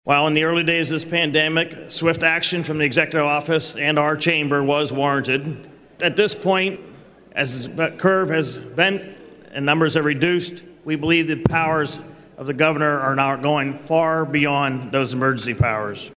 During debate on the house floor, House Majority Leader Kerry Benninghoff said Wolf’s Covid-19 disaster declaration is no longer needed.